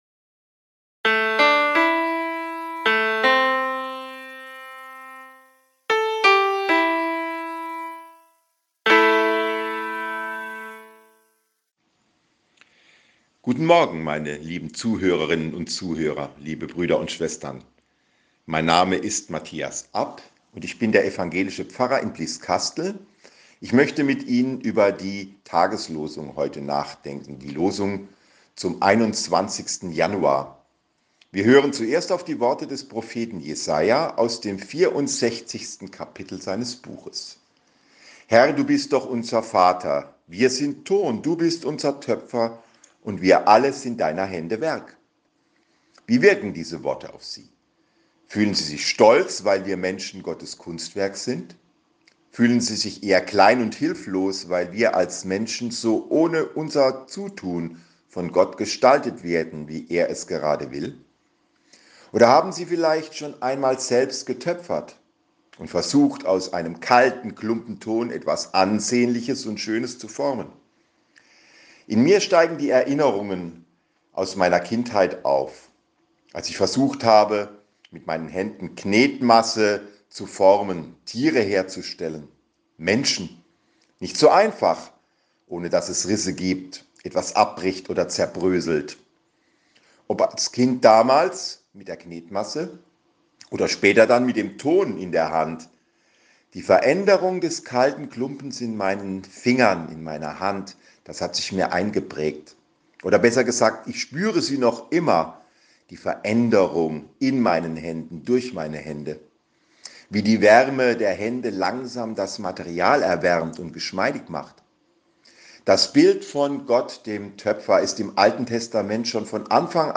Losungsandacht für Freitag, 21.01.2022